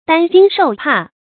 擔驚受怕 注音： ㄉㄢ ㄐㄧㄥ ㄕㄡˋ ㄆㄚˋ 讀音讀法： 意思解釋： 驚：驚恐。